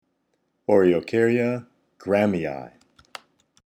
Pronunciation:
O-re-o-car-ya gráham-i-i